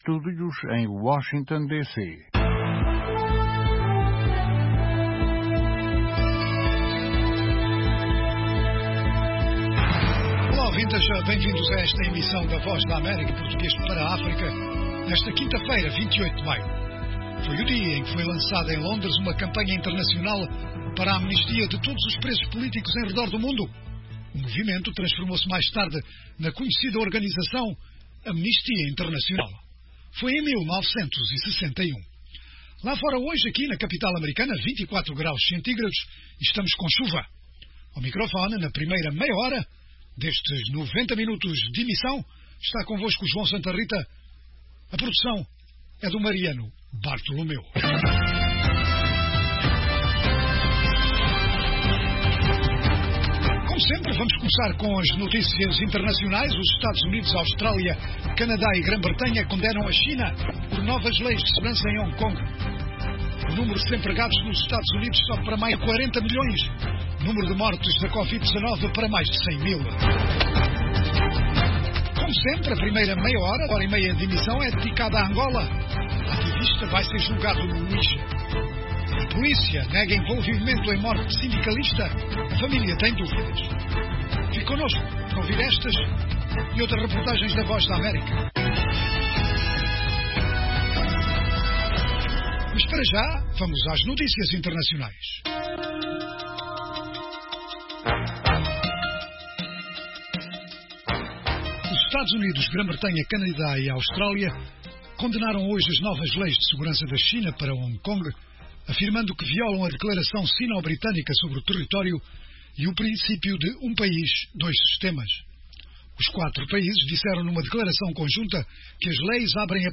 Oferece noticias, informação e analises e divide-se em três meias-horas: 1) Orientado a Angola - com histórias enviadas de Angola, por jornalistas em todo o país sobre os mais variados temas. 2) Notícias em destaque na África lusófona e no mundo, 3) Inclui as noticias mais destacadas do dia, análises, artes e entretenimento, saúde, questões em debate em África.